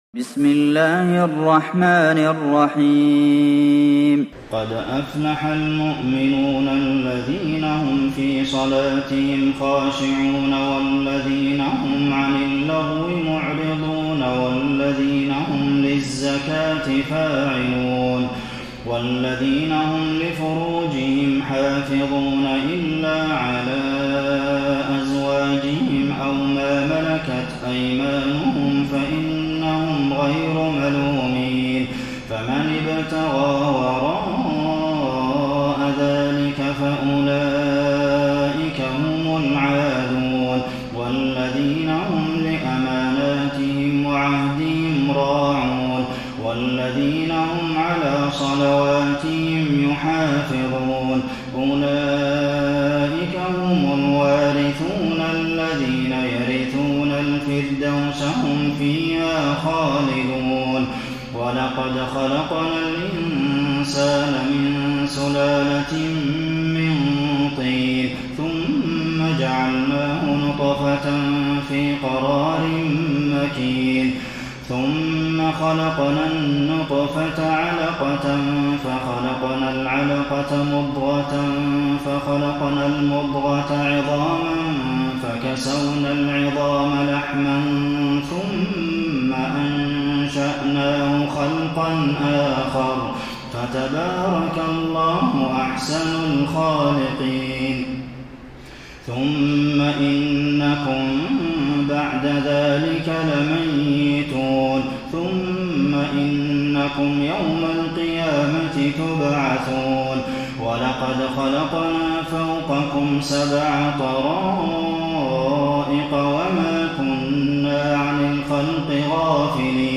تراويح الليلة السابعة عشر رمضان 1433هـ سورتي المؤمنون و النور (1-20) Taraweeh 17 st night Ramadan 1433H from Surah Al-Muminoon and An-Noor > تراويح الحرم النبوي عام 1433 🕌 > التراويح - تلاوات الحرمين